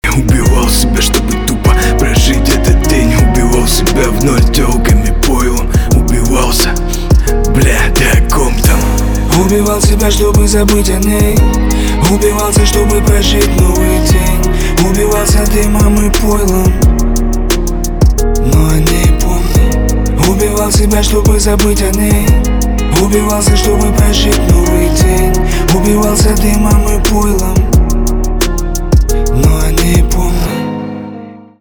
русский рэп , битовые , басы
грустные
пианино